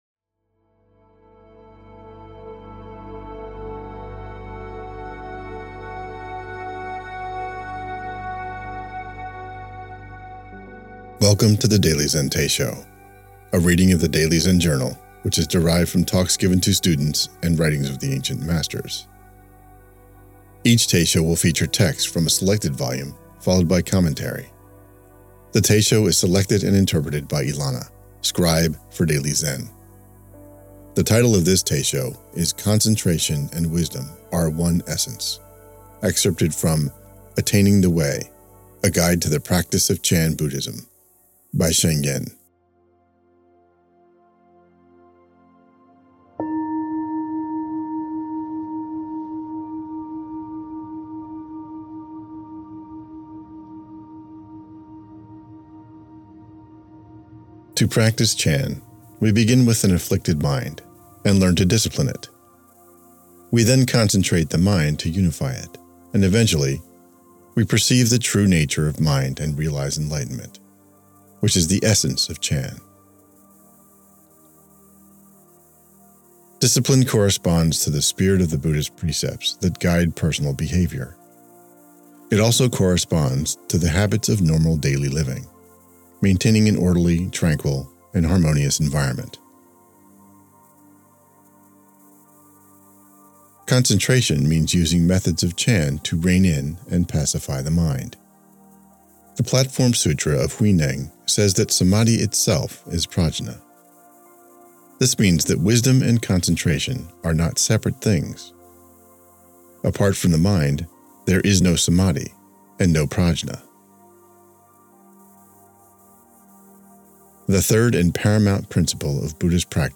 Teisho